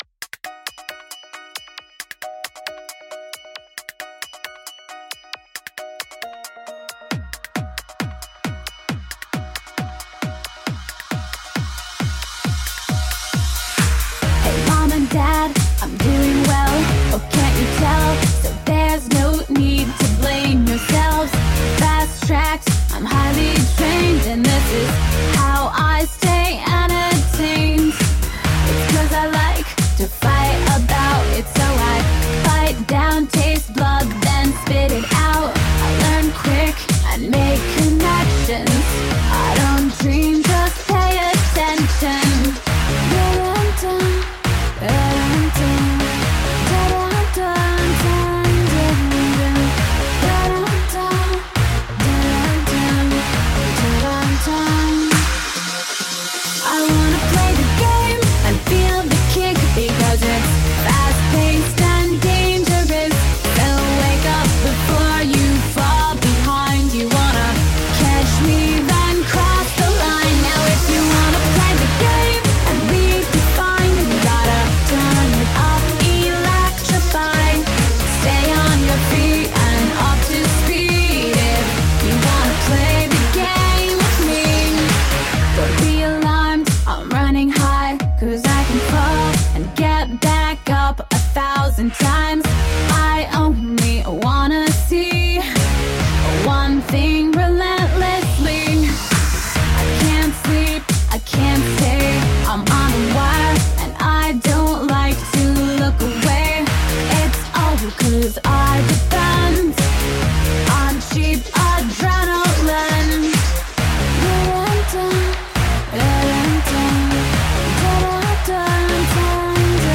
Catchy dance pop with insightful lyrics.
Tagged as: Electro Rock, Pop, Woman Singing Electro Pop